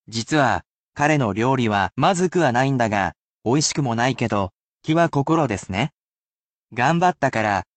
[casual speech]